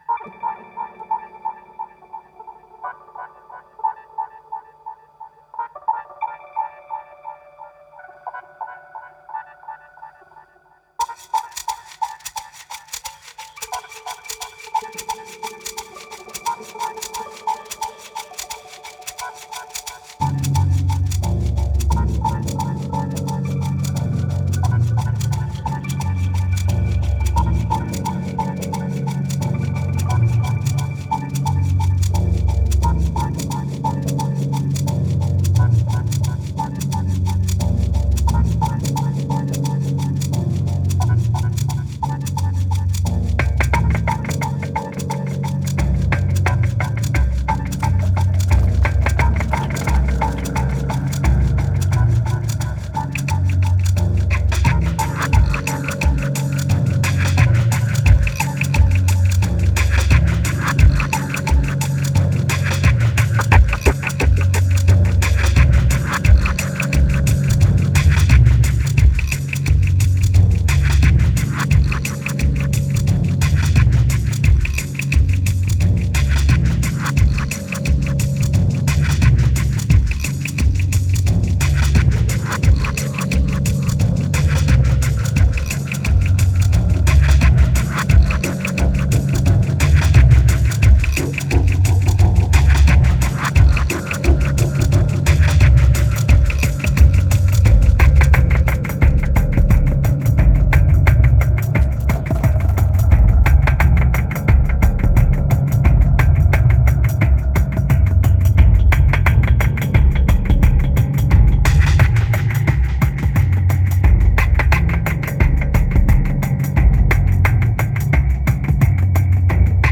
2204📈 - 44%🤔 - 88BPM🔊 - 2011-10-22📅 - 2🌟